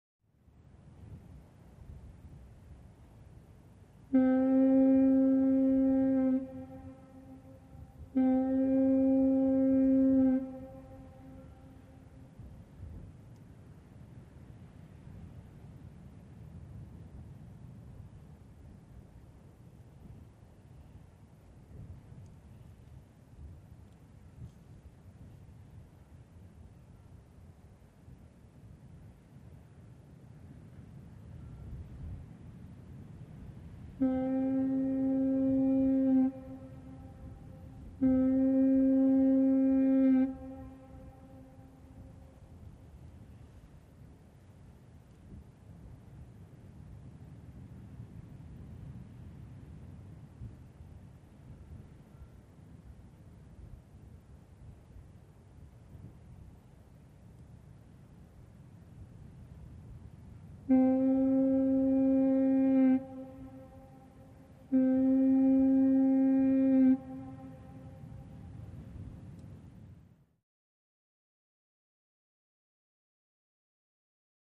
Harbor Ambience; Distant Fog Horn With Ring-off. Quiet Wind.